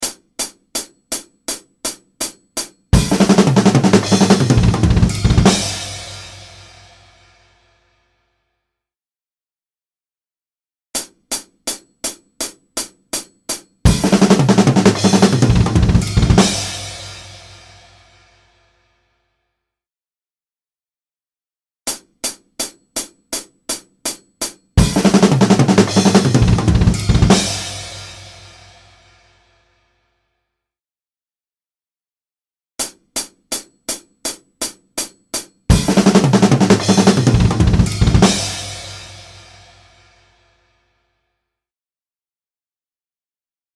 Trademark Fill
He leads with his left hand and peppers the last half of the fill with double-kick bursts and some interesting cymbal bell placements.